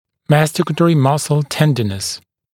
[‘mæstɪkətərɪ ‘mʌsl ‘tendənəs][‘мэстикэтэри ‘масл ‘тэндэнес]болезненность жевательных мышц